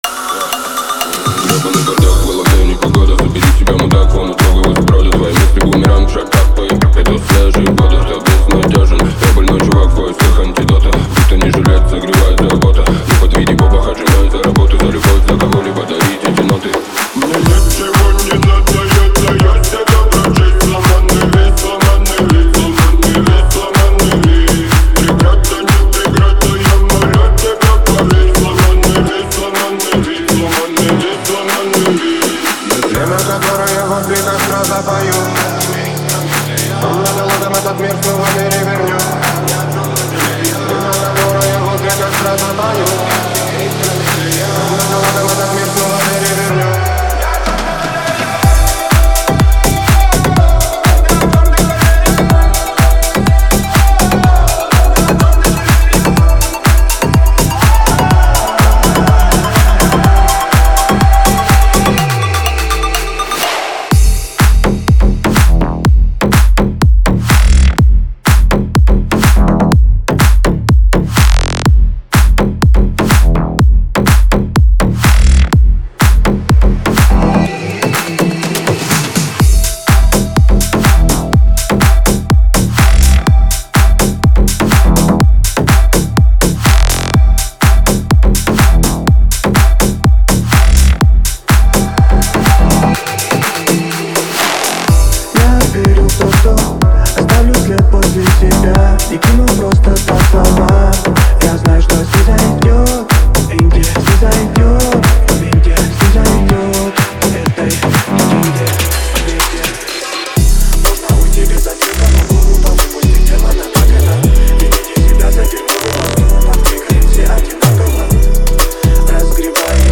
это яркий трек в жанре хип-хоп с элементами R&B